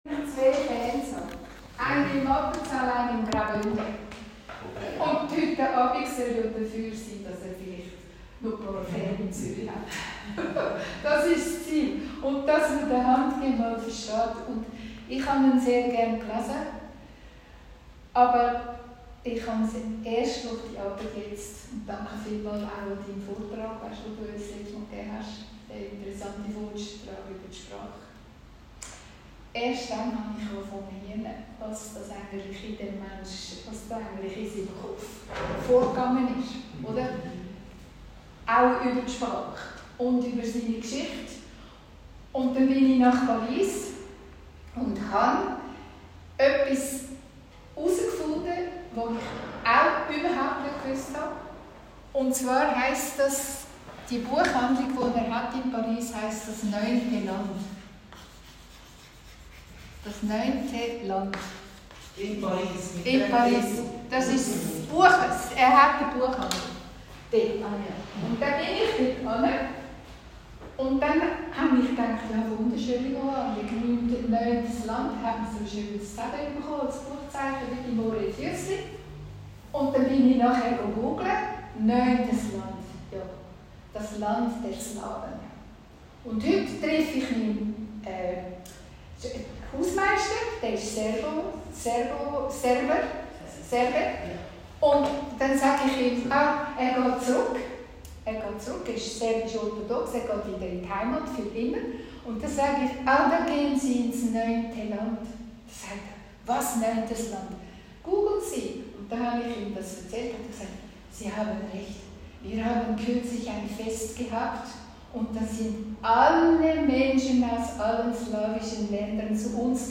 In der Hoffnung, ``dass es weiter geht, ja weiter geht...`` Ihm zu Ehren ein paar, auch geschichtliche und analytische Worte und rechtzeitig für in den sommerlichen Ferienkoffer, ein paar Buchtitel aus seinem immensen Werk. Eine Audio-Aufzeichnung des Vortages